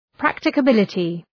Προφορά
{,præktıkə’bılətı}